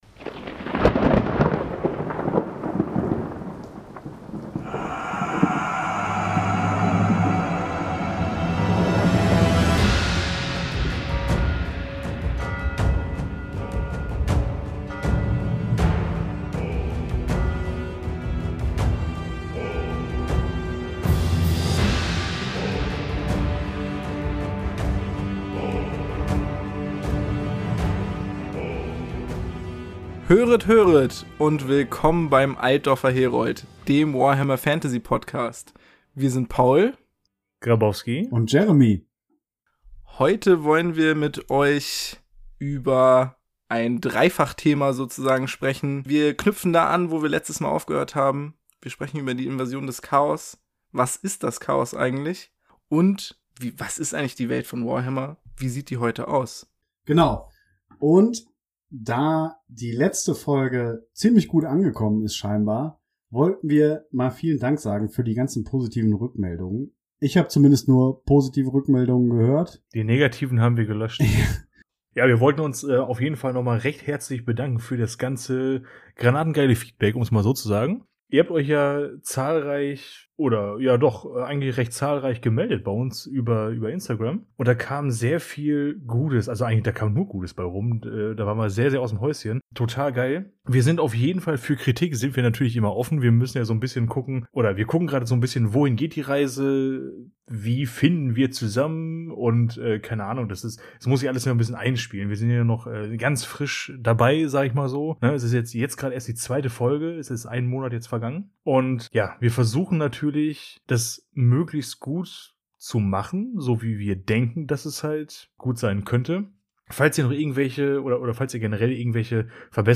Leider ist diese Folge nicht ohne Makel davon gekommen: Ein neuer, nicht optimal eingestellter Fernsprecher, korrumpierte Audiospuren und andere Hexerei machte das Schneiden fast so verwirrend wie ein Rätsel von Tzeentch. Wir hoffen ihr habt trotzdem Freude daran, wenn über den Einfall des Chaos und den Widerstand der Elfen, die korrumpierenden Winde des Chaos und die weitreichende Geographie der Warhammer-Welt schwadroniert wird.